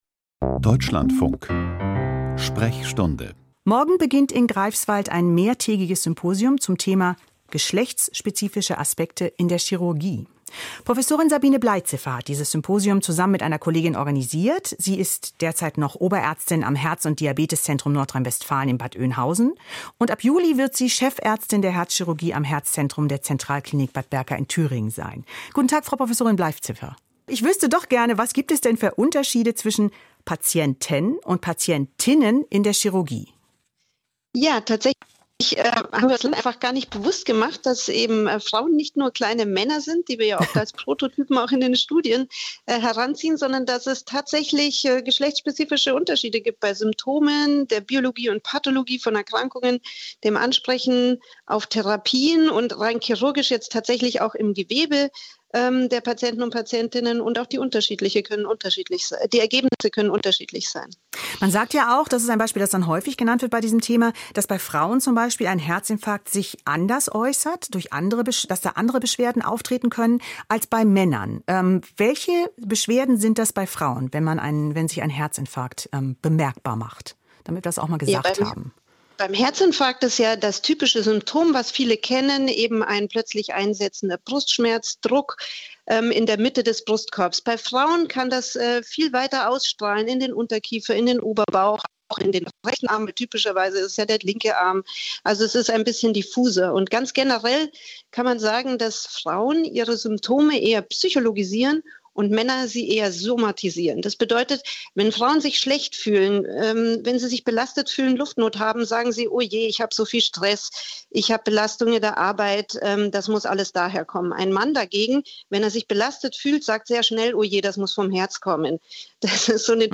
Geschlechtsspezifische Aspekte in der Herzchirurgie: Interview